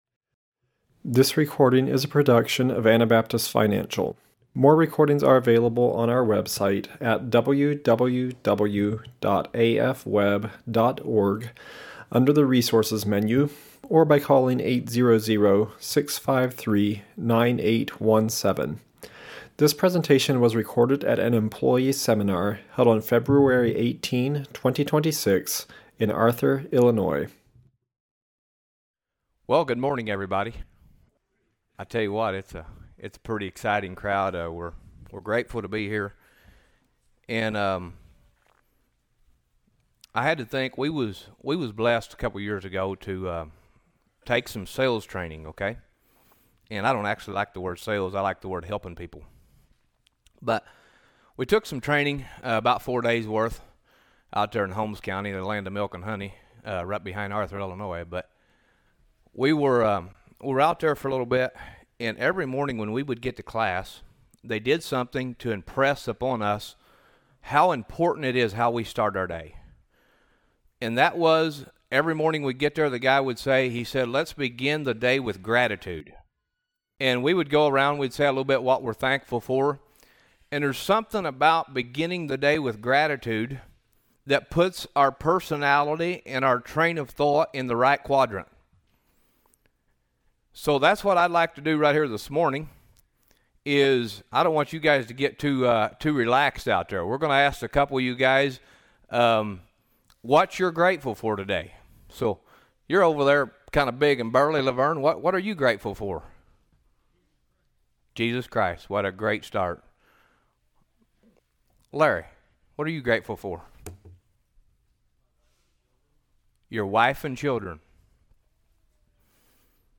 Illinois Employee Seminar 2026